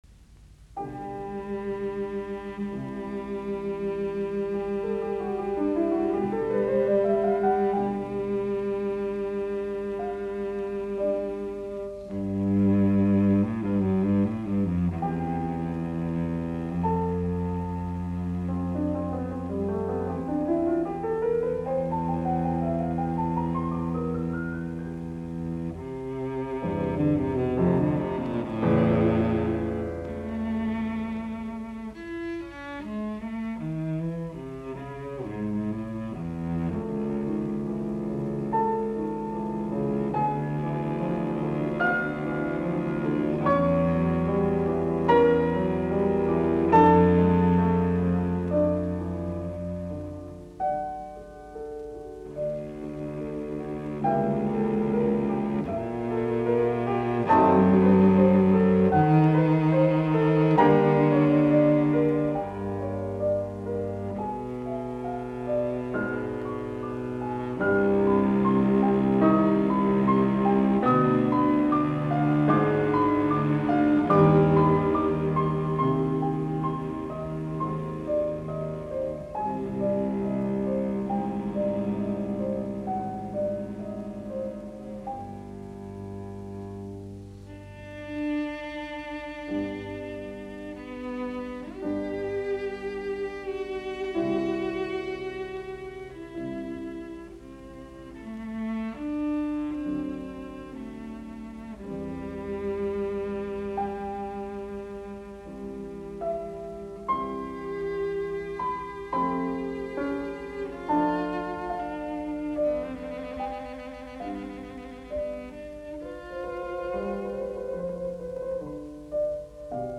Sonaatit, sello, piano, op102. Nro 1, C-duuri
Soitinnus: Sello, piano.